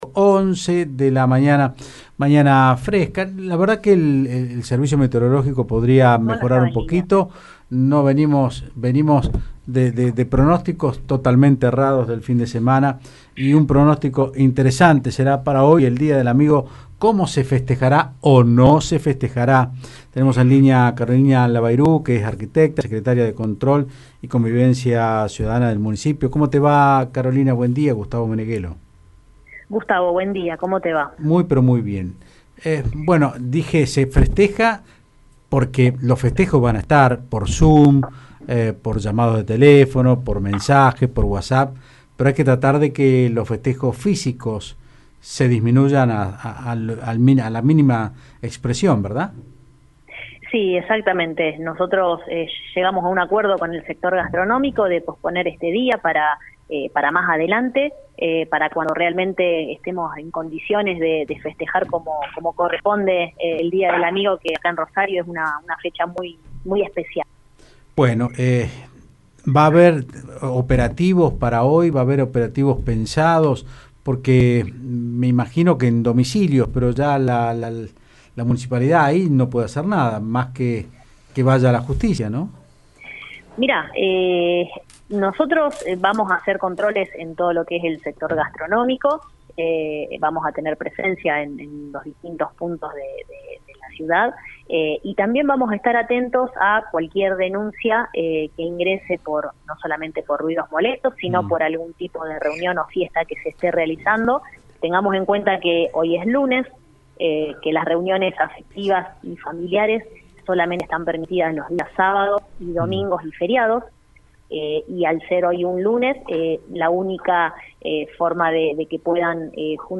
La secretaría de Control y Convivencia municipal Carolina Labayru dijo en Otros Ámbitos (Del Plata Rosario 93.5) sólo estarán permitidas las juntadas en bares y restaurantes, que tuvieron un nivel bajo de actividad desde el viernes.